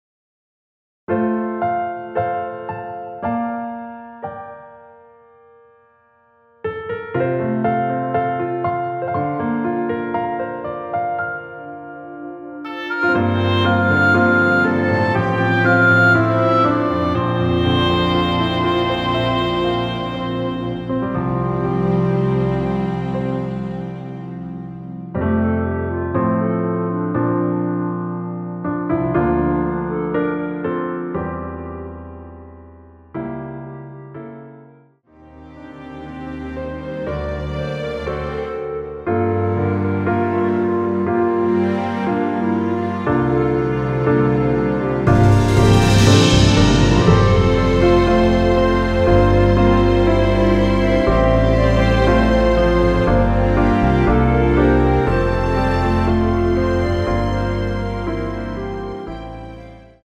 원키에서(-2)내린(1절앞+후렴) 멜로디 포함된 MR입니다.(미리듣기 확인)
◈ 곡명 옆 (-1)은 반음 내림, (+1)은 반음 올림 입니다.
앞부분30초, 뒷부분30초씩 편집해서 올려 드리고 있습니다.
중간에 음이 끈어지고 다시 나오는 이유는